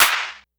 Snare_14.wav